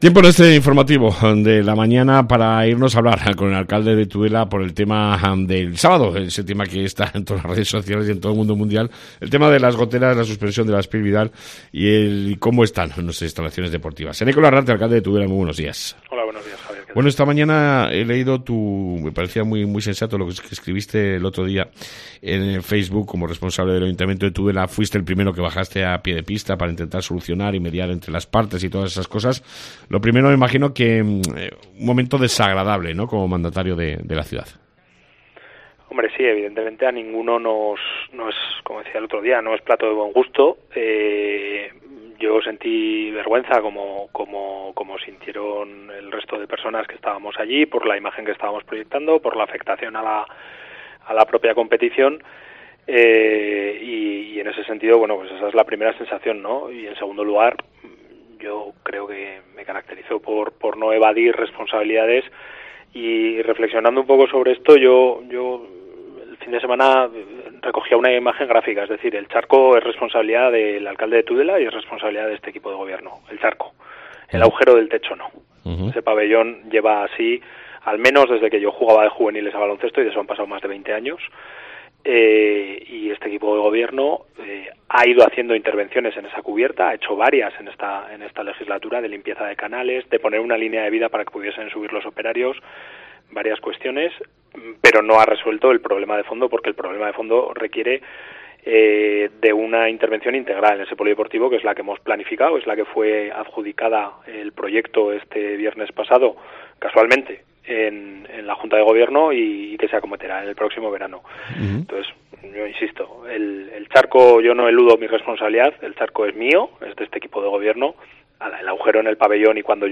Entrevista al Alcalde Eneko Larrarte